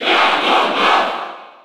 Category:Crowd cheers (SSB4) You cannot overwrite this file.
Ganondorf_Cheer_French_NTSC_SSB4.ogg